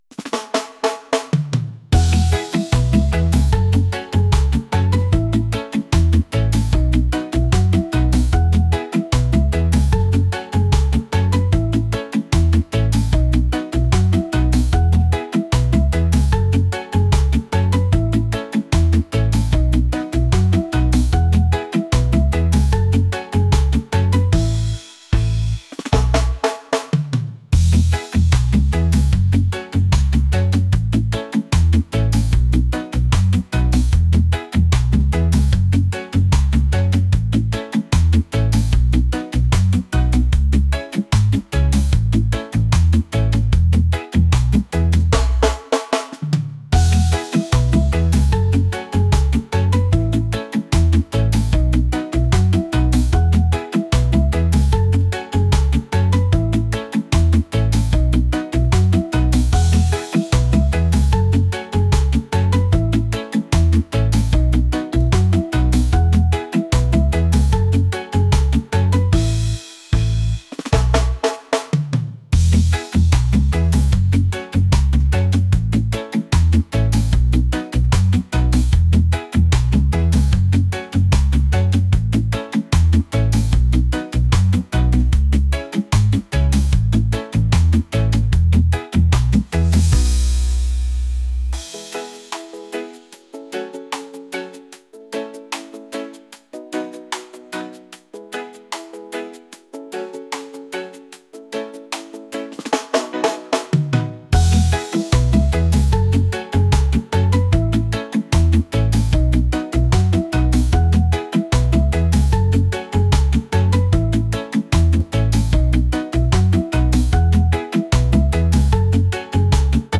reggae | pop | latin